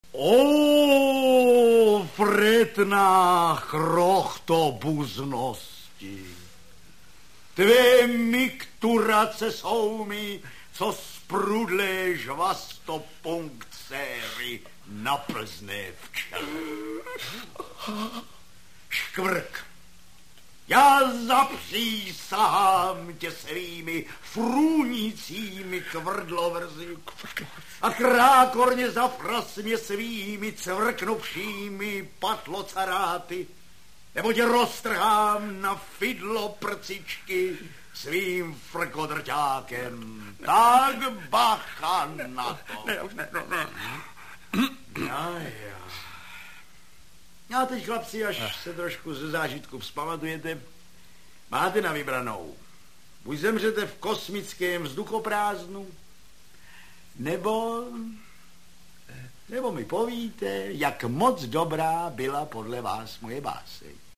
Jednou z tradičních akcí ručníkového dne je recitace Vogonské poezie, třetí nejhorší poezie ve vesmíru.
namluvené formě (MP3) a překladu do světových jazyků.